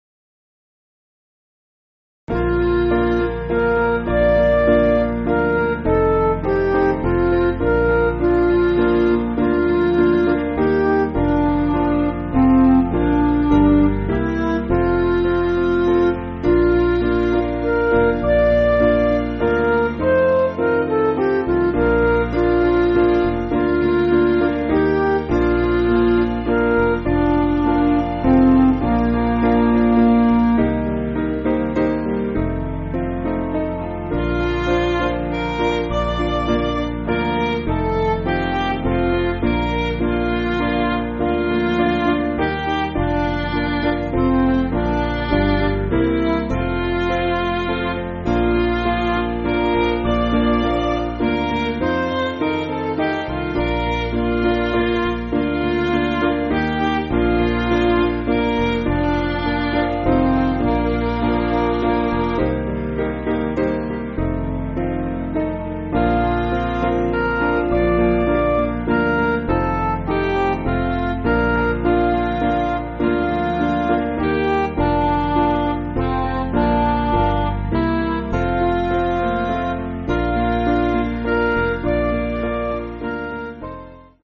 Piano & Instrumental
(CM)   4/Bb